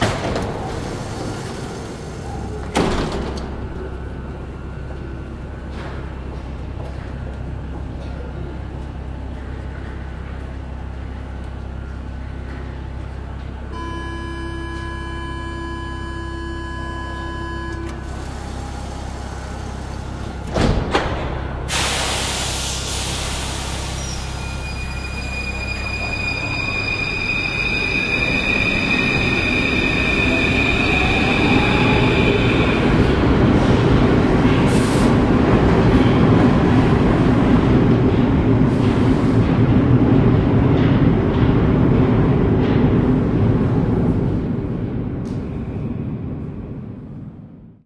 氛围酒吧户外
描述：用ZOOM H4录制的样本在Île de la Cité的一个酒吧里，大约02:00PM
Tag: 氛围 现场 法国 地铁 酒吧 录音 立体声 声音 声音